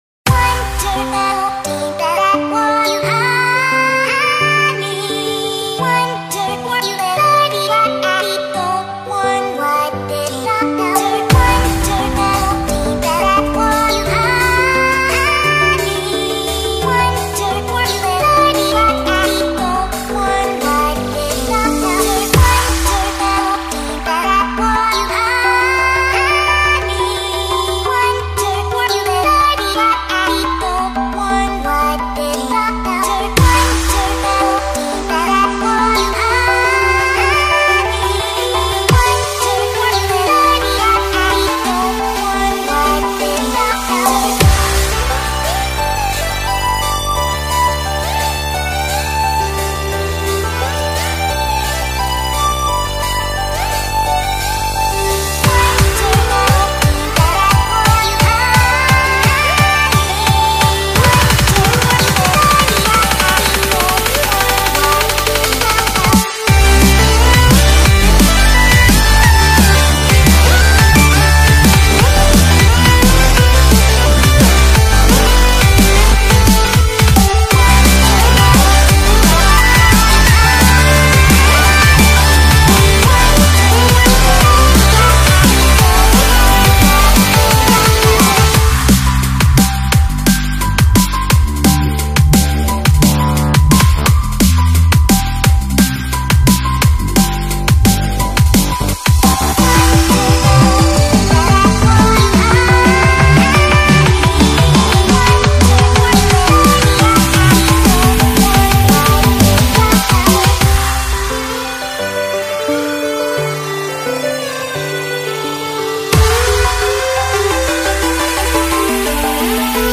My DnB remix
Genre : DnB BPM : 174